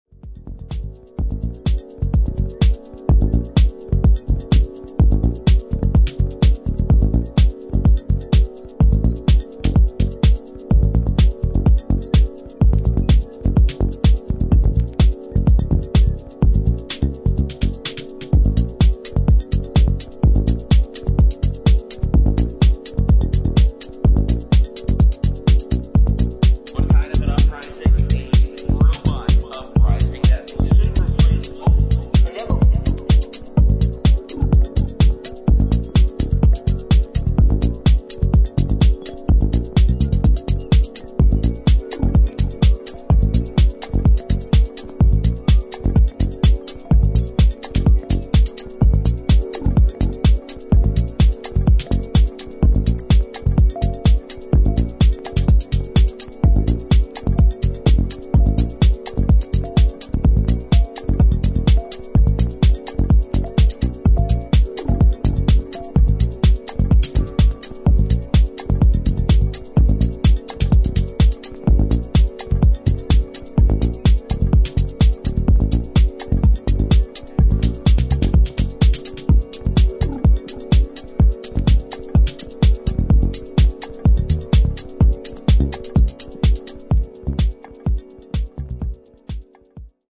303風味のアシッドなベースサウンドをレイヤー的に用い、ドライブ感のある現代的なミニマルハウスへと昇華しています。